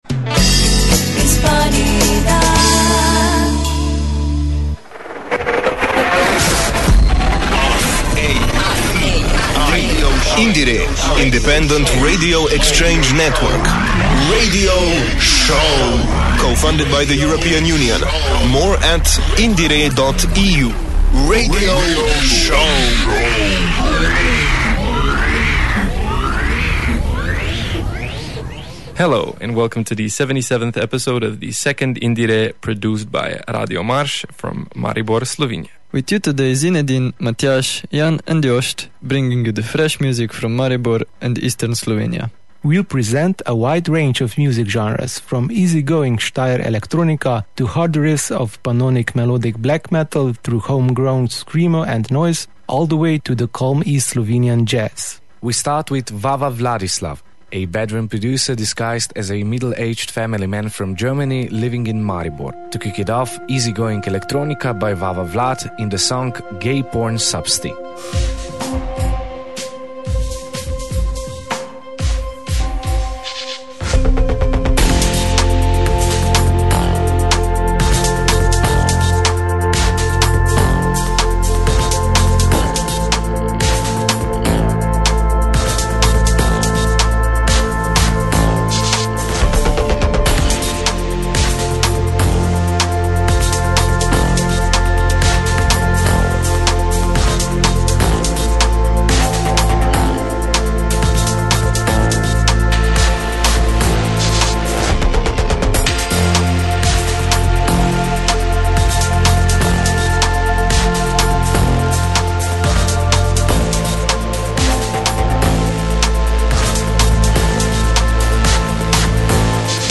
a fresh music